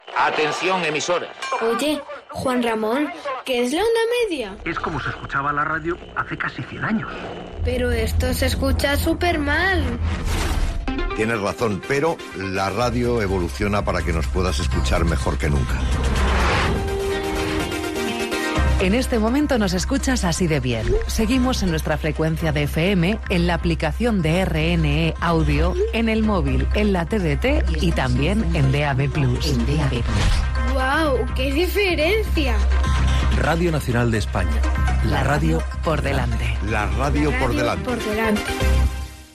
Tres promocions de l'apagada de les emissions en Ona Mitjana de RNE.